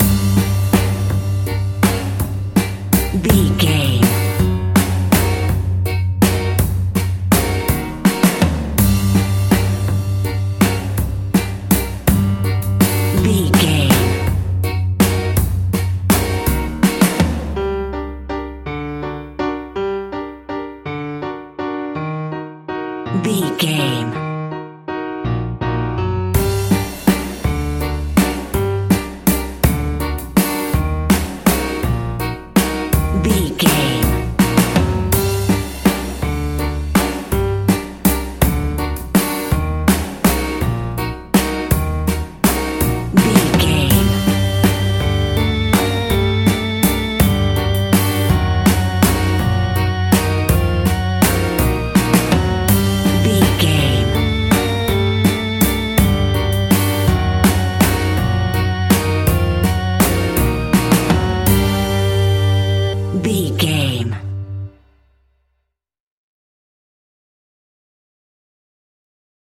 Aeolian/Minor
scary
ominous
dark
eerie
piano
synthesiser
drums
electric organ
strings
spooky
horror music